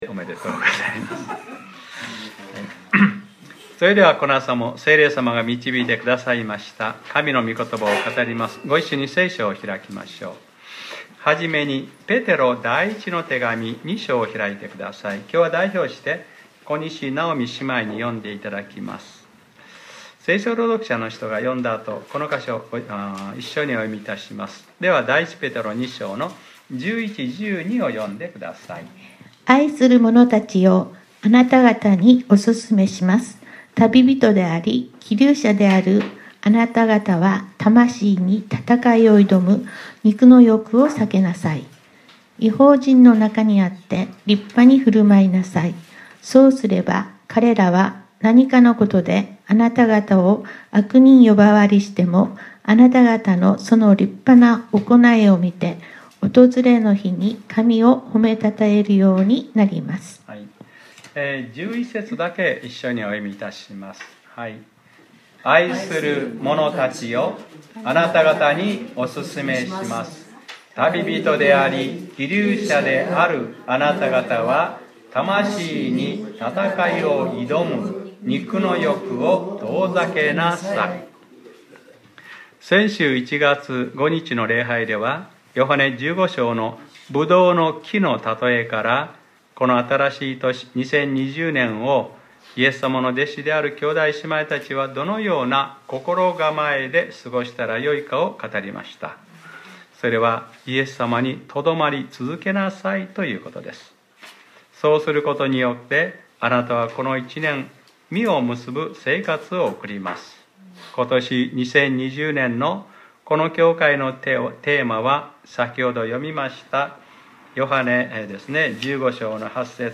2020年01月12日（日）礼拝説教『旅人であり寄留者であるあなたは』